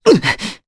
Pavel-Vox_Damage_jp_02.wav